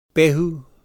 ngāti porou